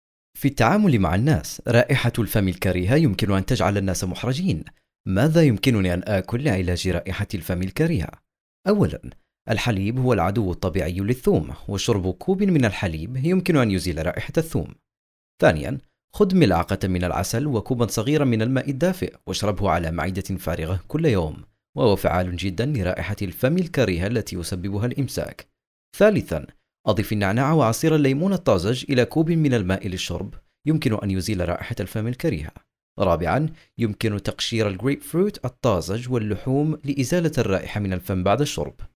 配音风格： 低沉 稳重 大气 浑厚 磁性 沉稳 优雅 伤感